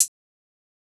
Closed Hats
SouthSide Hi-Hat (29).wav